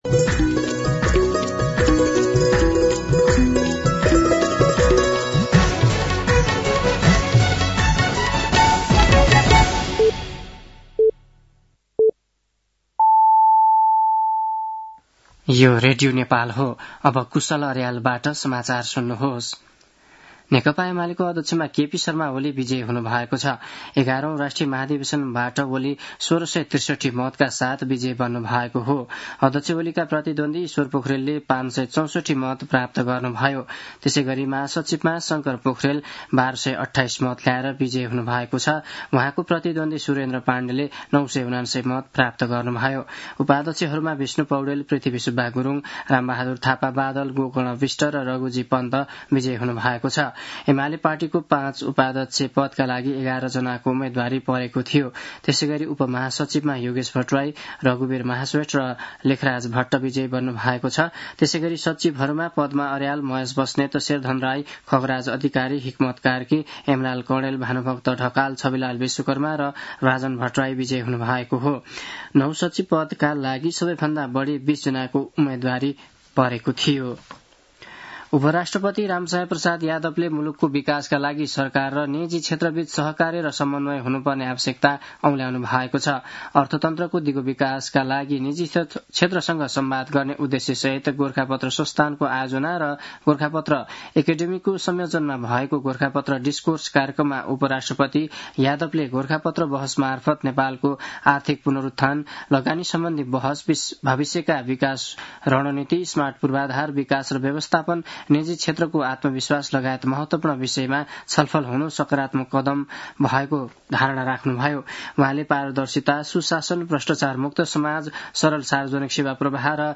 साँझ ५ बजेको नेपाली समाचार : ३ पुष , २०८२
5-pm-news-9-3.mp3